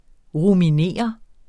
Udtale [ ʁumiˈneˀʌ ]